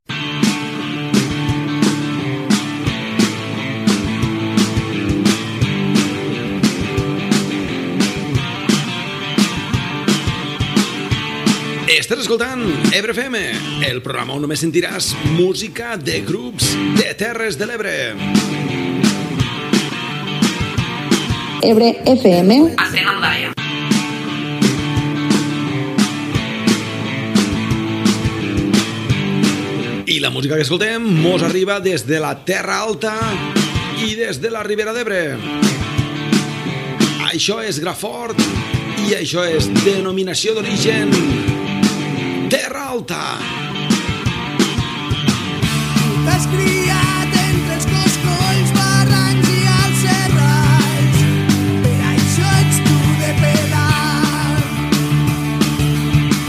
Inici del programa amb identificació i tema musical.
Musical